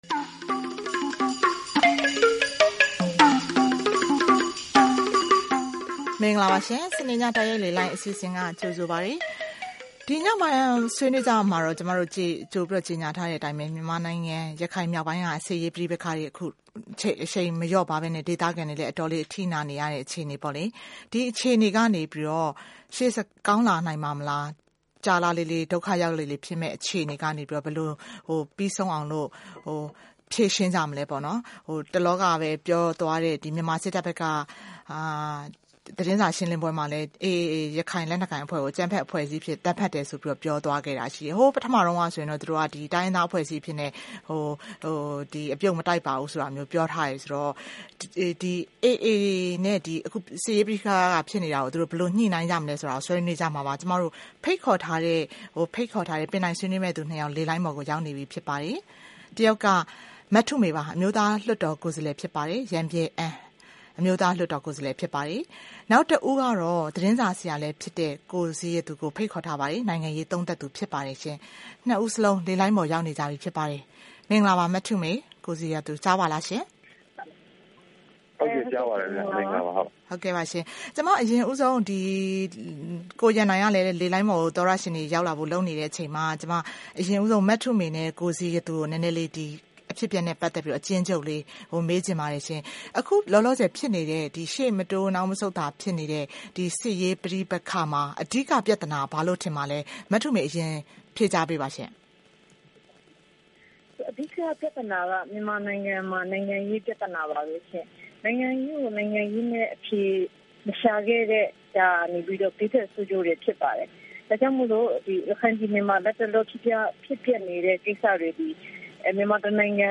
ရခိုင်ပြည်ငြိမ်းချမ်းရေး အလားအလာ (စနေည တိုက်ရိုက်လေလှိုင်း)